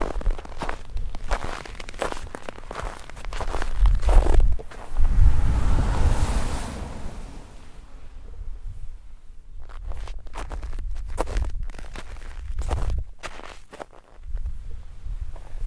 walking in snow